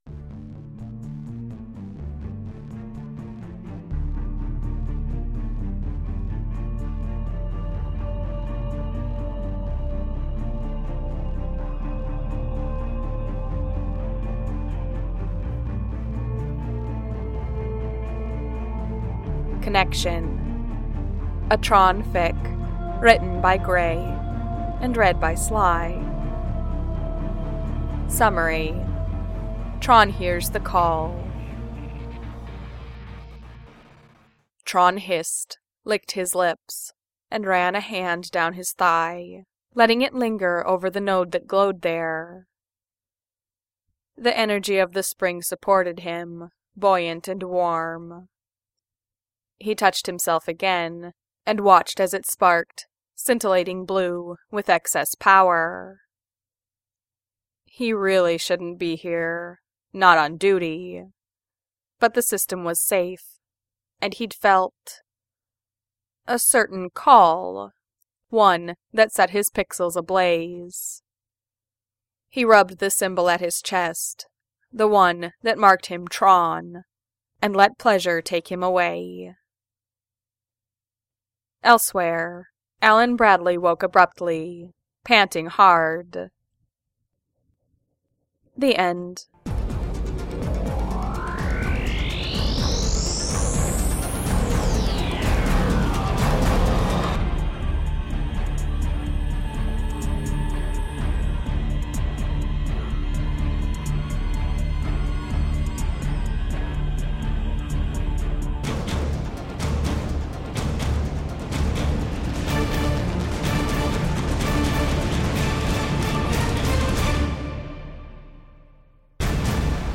TRON fans out there might recognize the purple circuitry on the podfic cover, but for anyone new to the fandom (first of all, WELCOME!